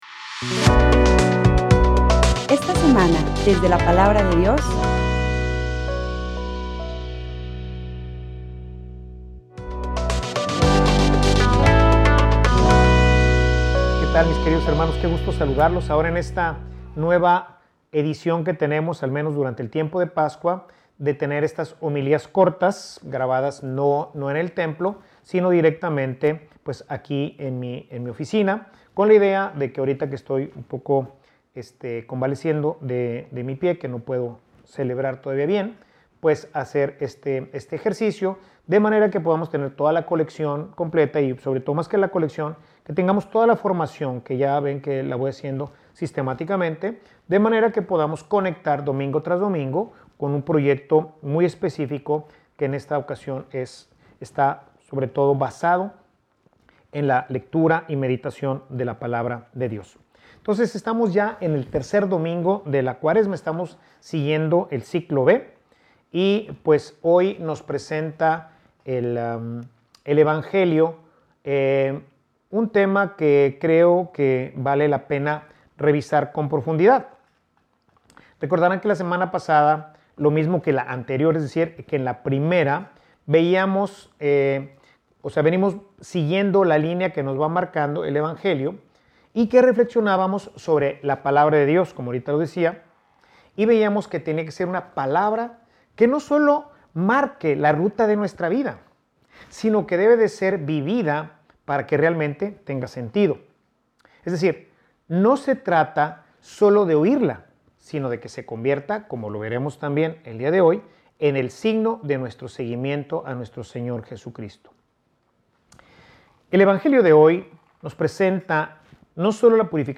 Homilia_Una_fe_sospechosa.mp3